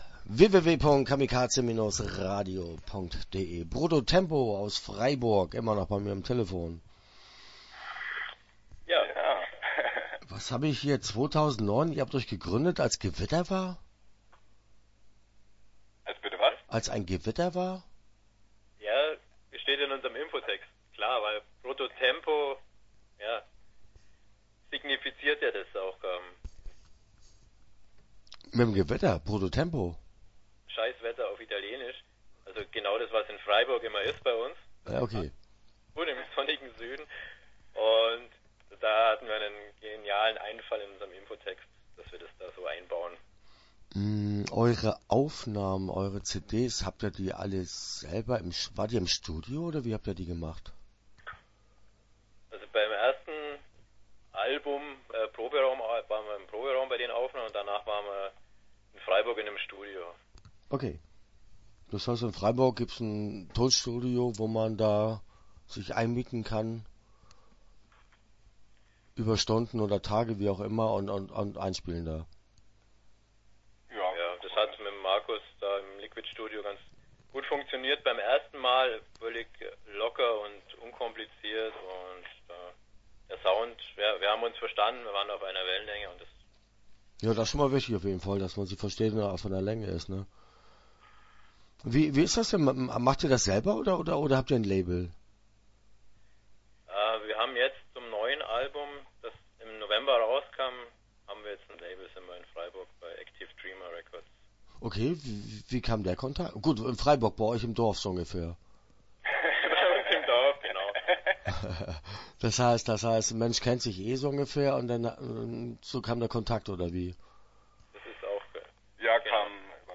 Start » Interviews » Brutto Tempo